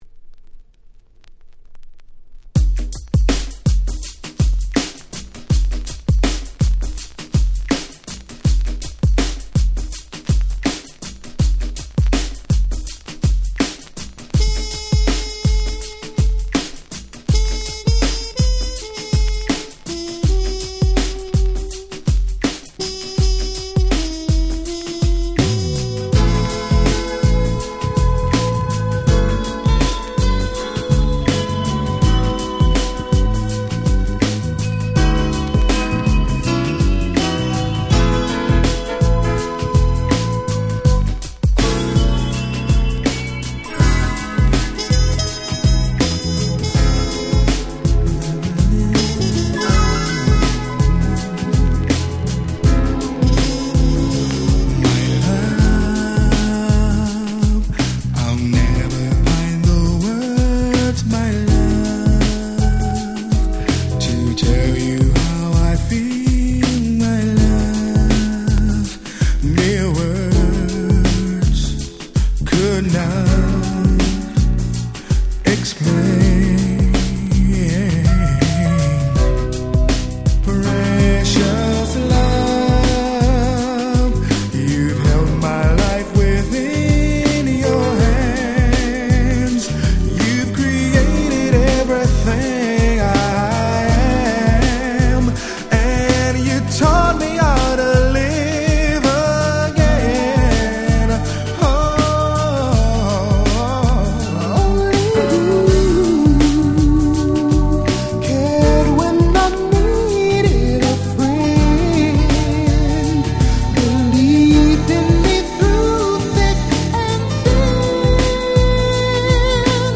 グラウンドビート